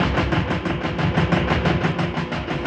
Index of /musicradar/rhythmic-inspiration-samples/90bpm
RI_DelayStack_90-01.wav